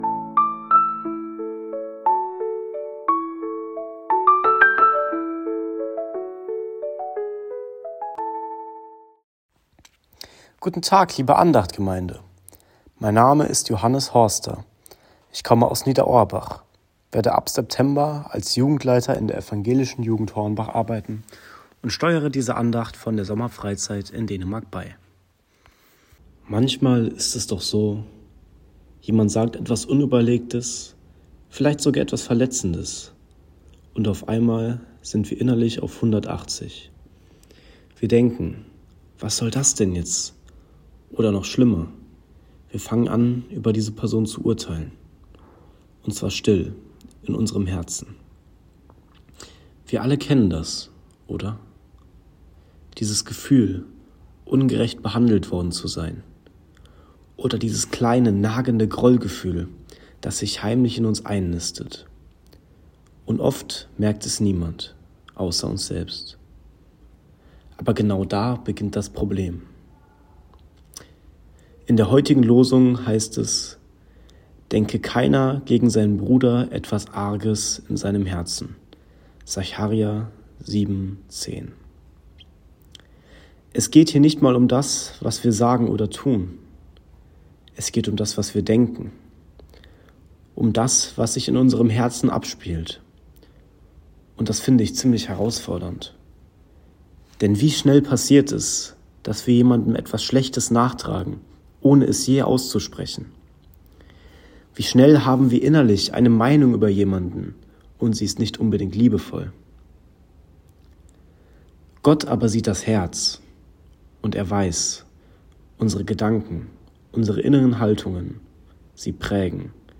Losungsandacht für Samstag, 02.08.2025